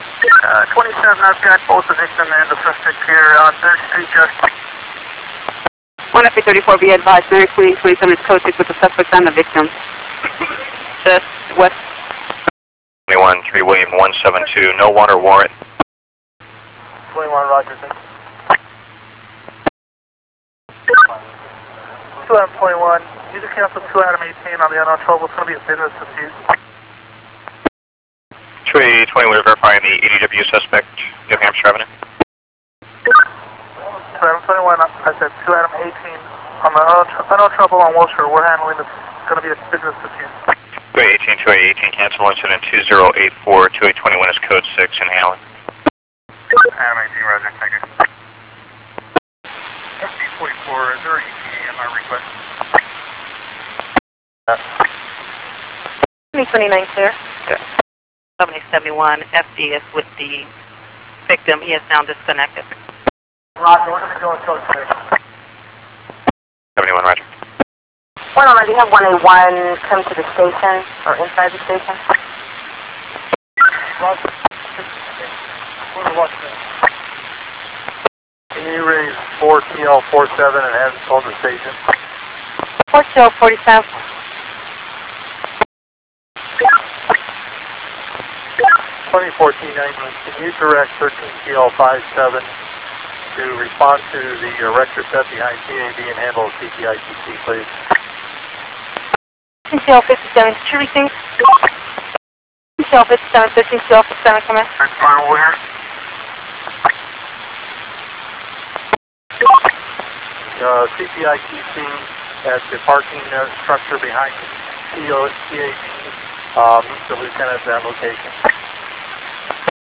police.wav